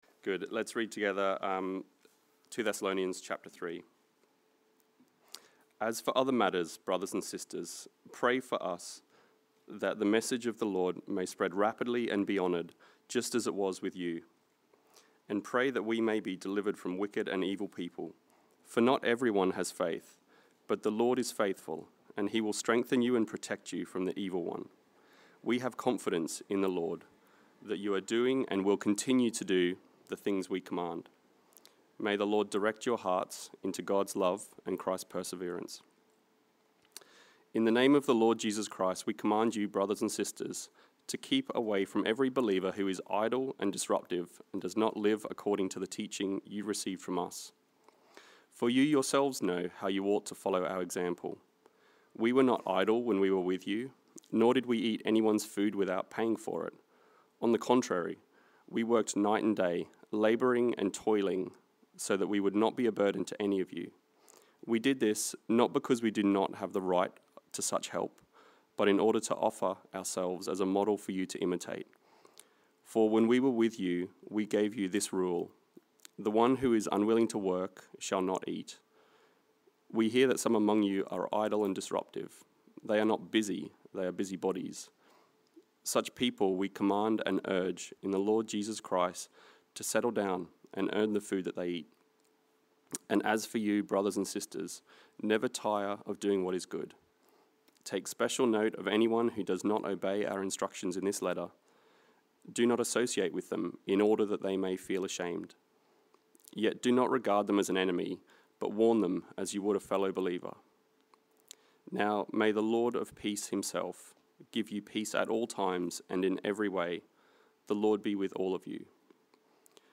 This is the forth and final sermon in our series Stand Firm. It focussed on 2 Thessalonians 3:1-18 and the outline was: 1. Pray about the Lord's word 2. Work out the Lord's command 3. Enjoy the Lord's presence